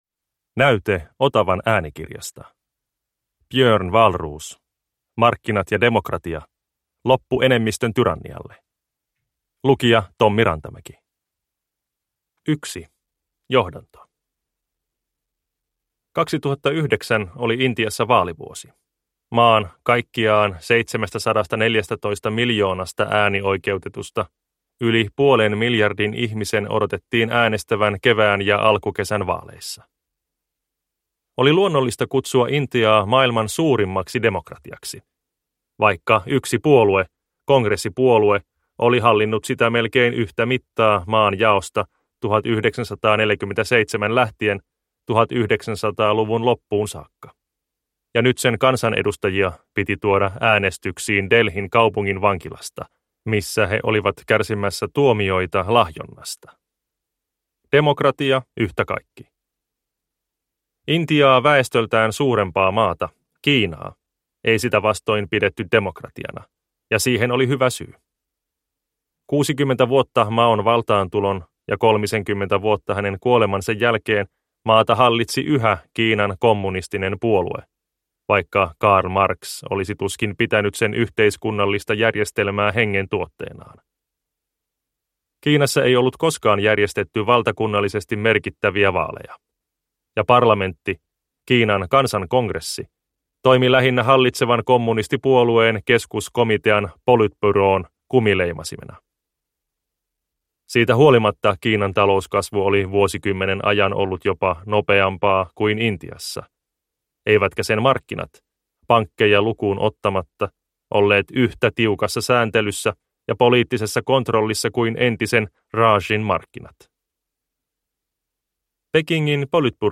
Markkinat ja demokratia – Ljudbok – Laddas ner